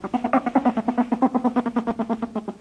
animalworld_volverine.ogg